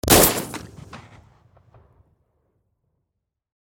shotgun.wav